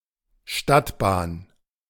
Stadtbahn (German pronunciation: [ˈʃtatˌbaːn]